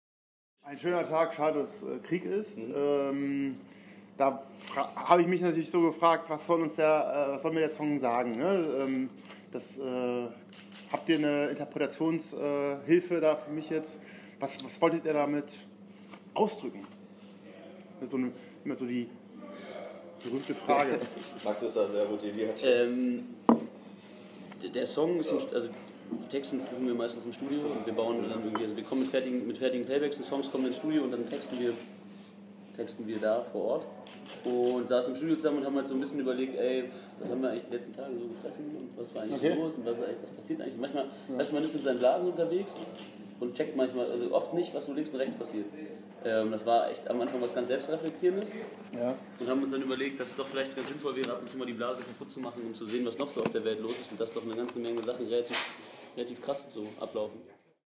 Die Jungs lümmeln ein bisschen rum, wie man das so macht im Backstage-Bereich.